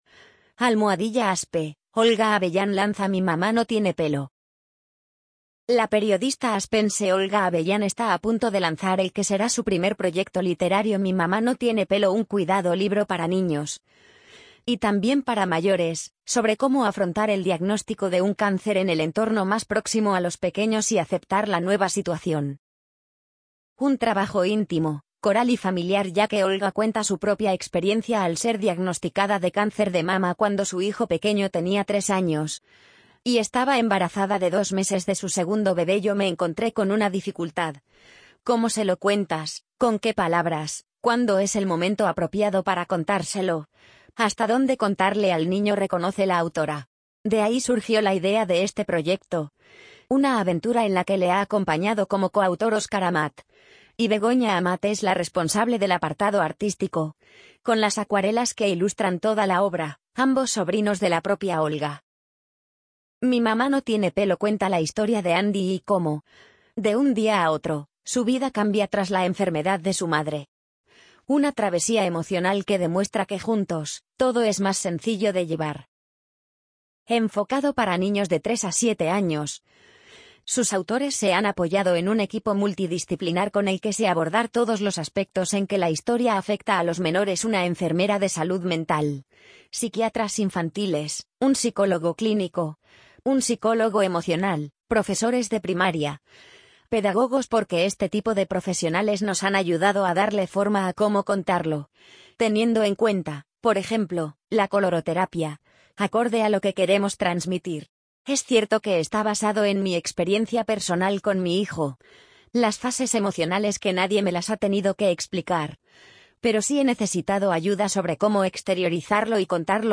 amazon_polly_50505.mp3